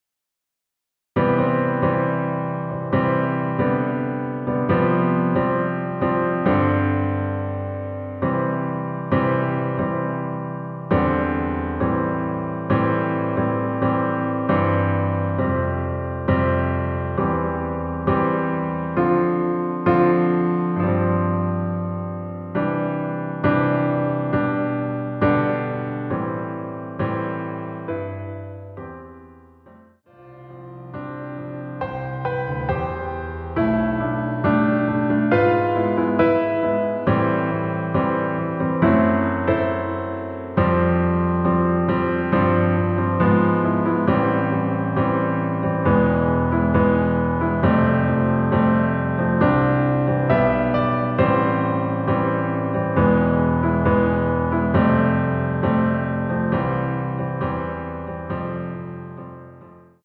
반주를 피아노 하나로 편곡하여 제작하였습니다.
원키에서(-1)내린(Piano Ver.) (1절+후렴) MR입니다.